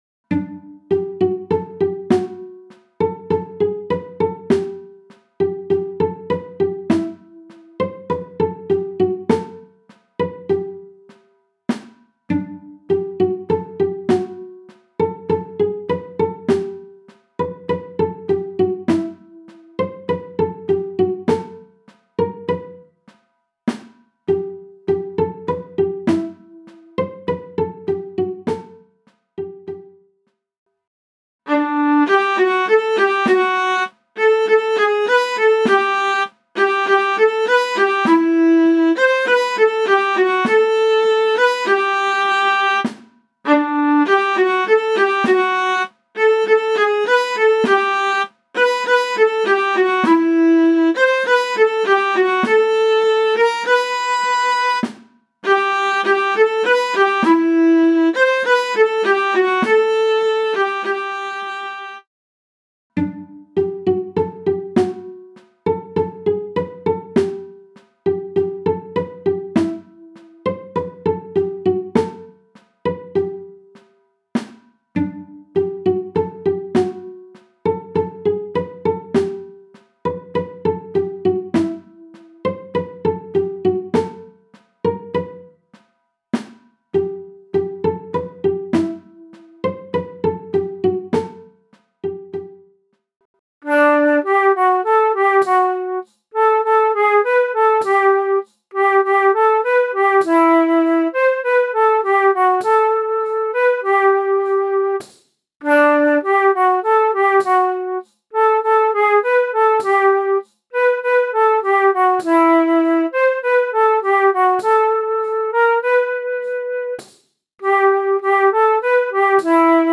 MIDI - 1-stimmig
midi_kleine-ballade_1-stimmig_320.mp3